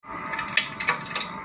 Poop noise